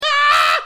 Tags: Cosy Corner Bruits de Bouches rires